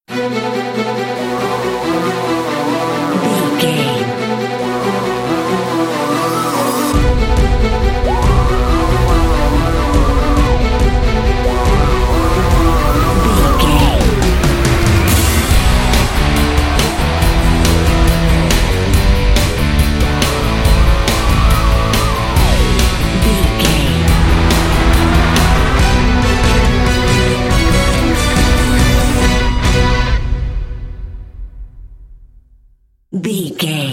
Epic / Action
Aeolian/Minor
strings
drums
cello
violin
percussion
orchestral hybrid
dubstep
aggressive
energetic
intense
synth effects
wobbles
driving drum beat